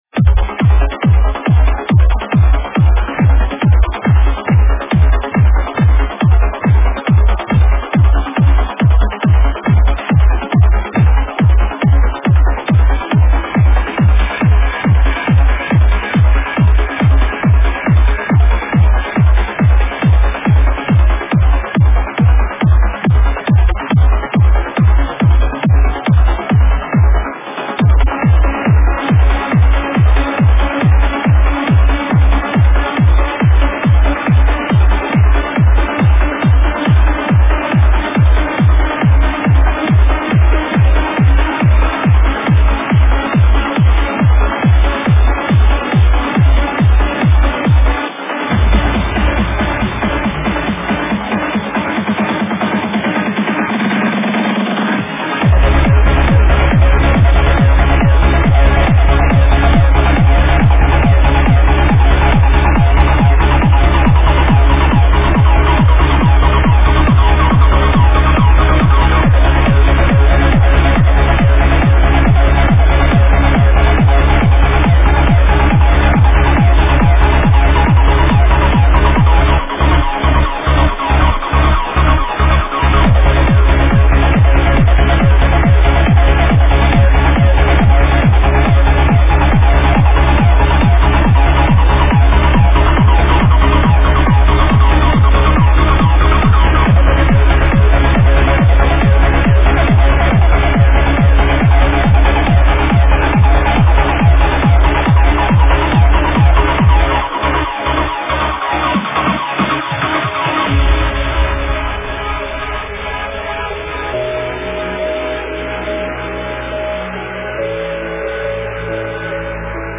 Назад в Psylifting / Tech Lifting / Trance / Tech Trance
Стиль: Trance